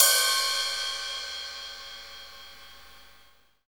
HAT FUZN H0L.wav